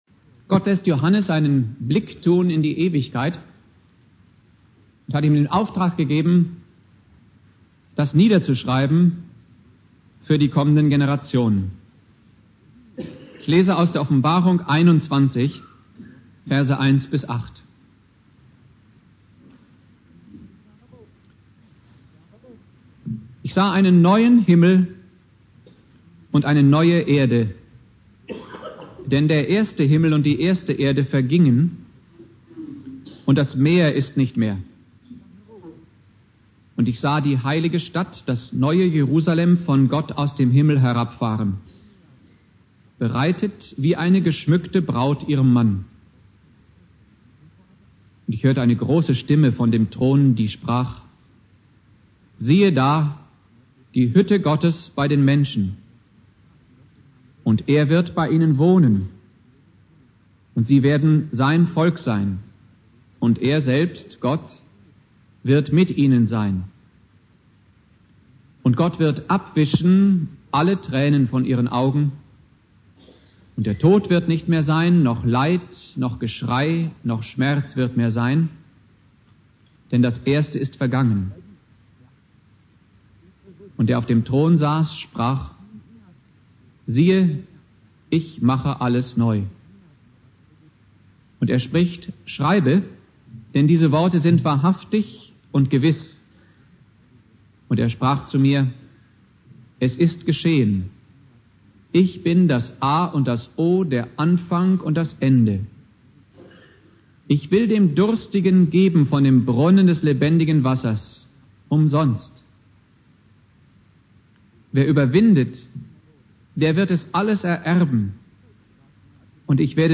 Predigt
Ewigkeitssonntag Prediger